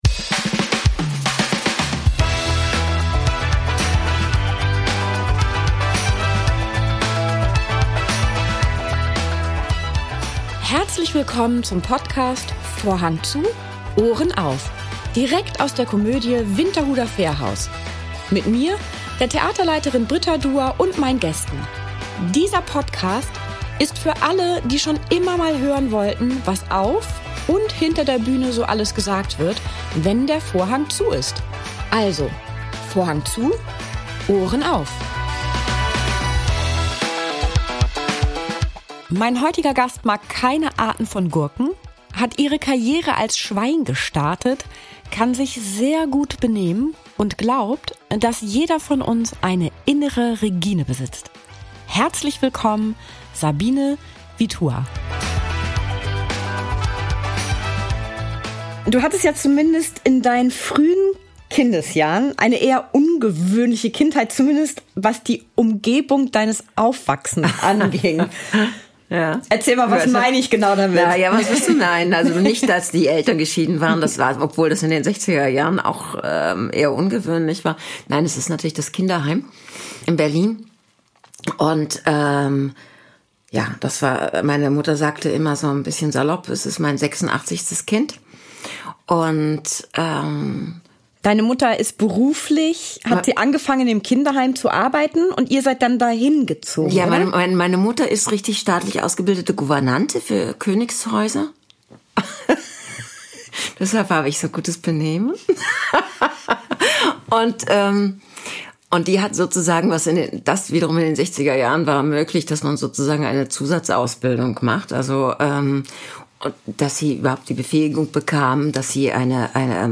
Zwischen den Vorstellungen kam sie in unser Podcast-Studio